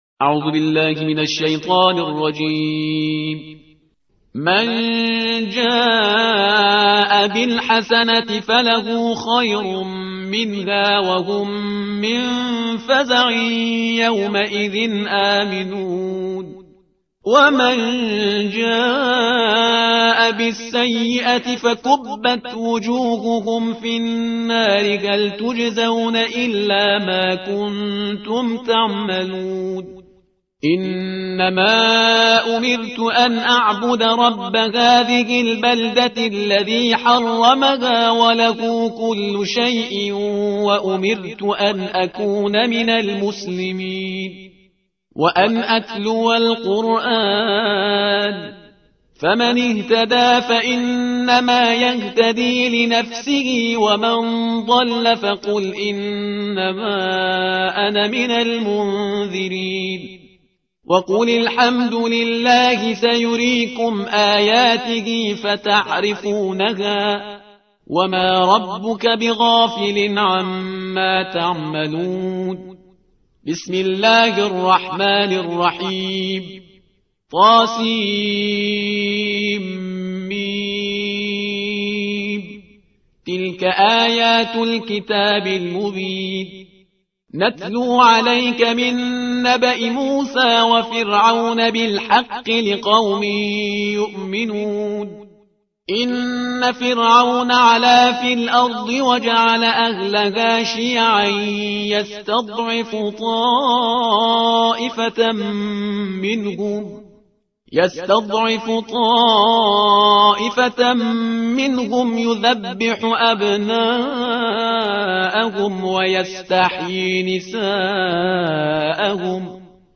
ترتیل صفحه 385 قرآن – جزء بیستم